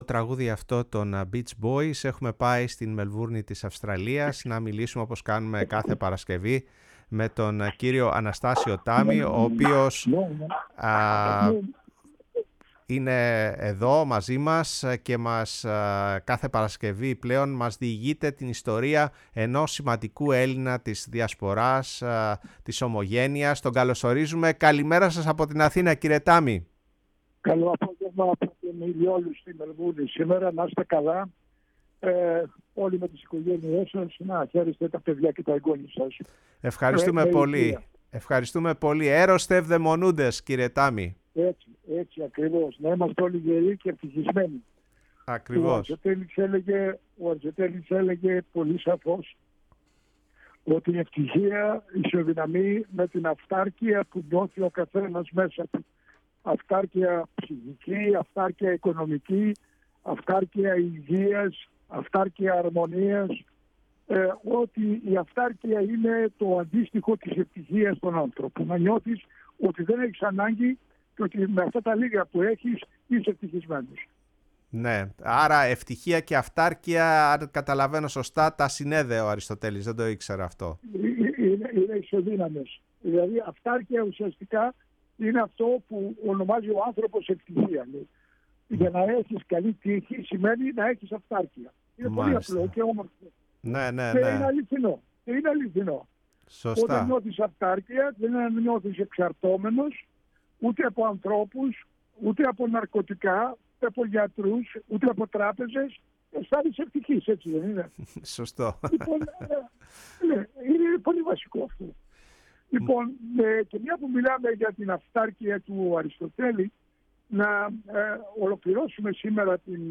μιλώντας στο ραδιόφωνο της Φωνής της Ελλάδας και στην εκπομπή “Η Παγκόσμια Φωνή μας”